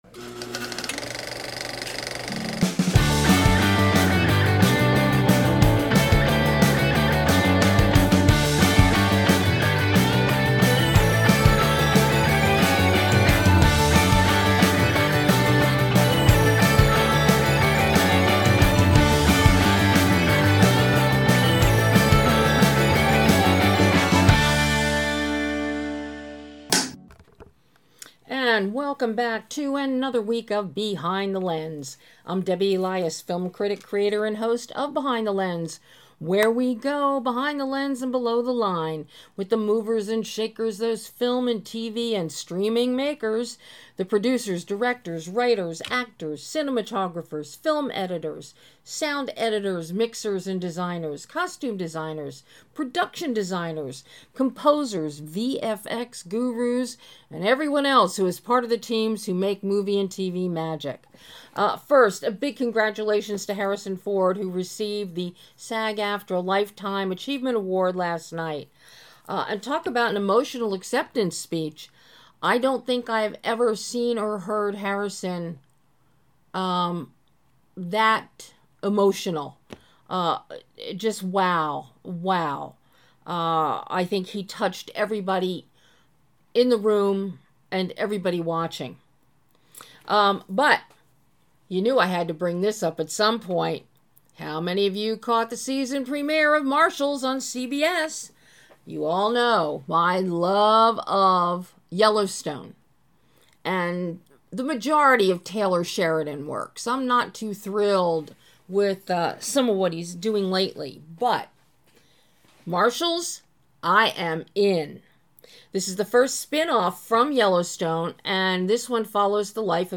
In this sharp, irreverent comedy, a disgraced fashion designer with a dangerously low credit score, Margarita, enters a reality show (a la Project Runway) with a six-figure cash prize that would save her babushka’s West Hollywood apartment.